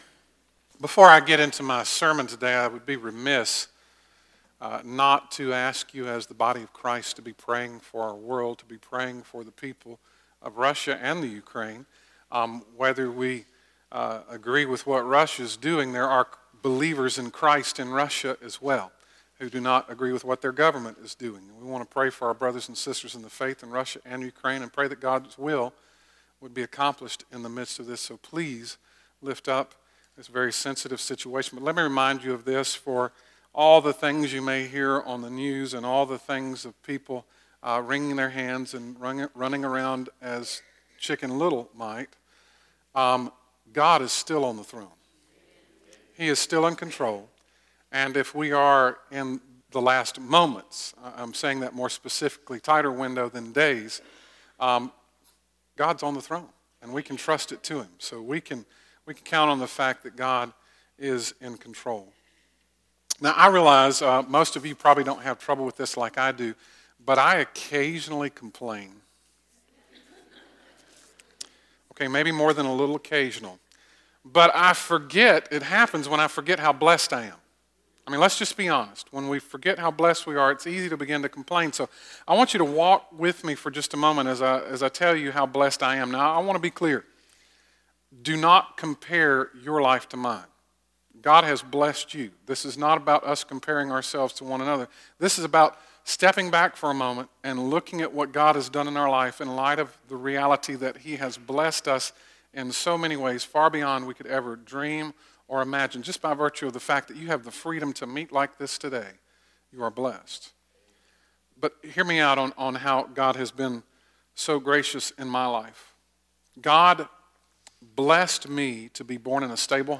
So Blessed - Pleasant Grove Baptist Church Ellijay Georgia